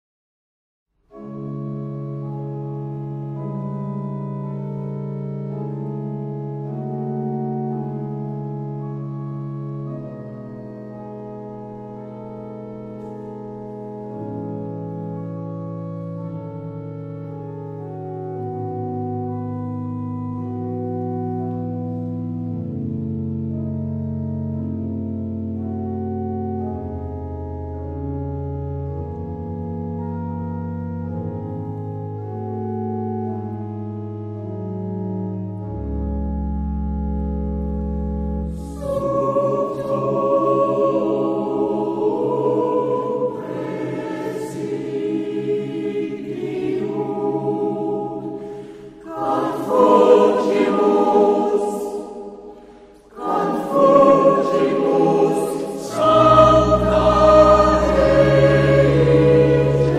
Epoque: 20th century
Genre-Style-Form: Sacred ; Motet
Type of Choir: SATB  (4 mixed voices )
Instruments: Organ (1)
Tonality: D minor